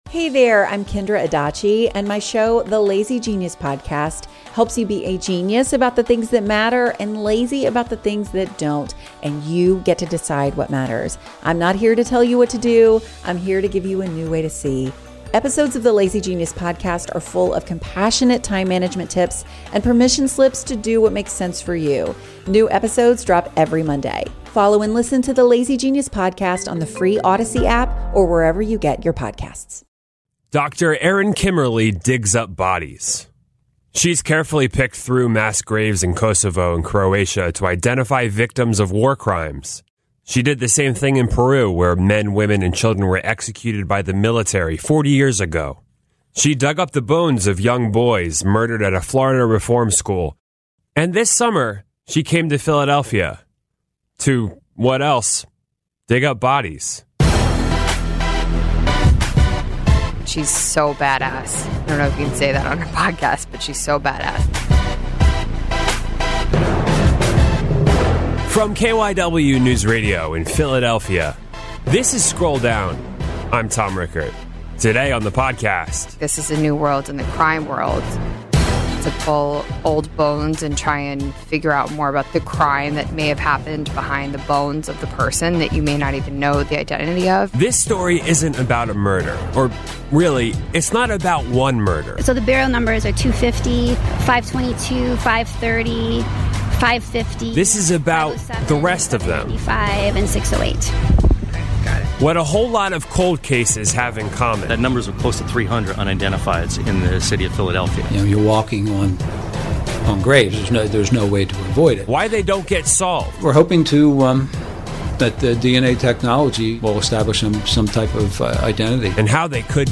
in the KYW Newsradio studios in Philadelphia.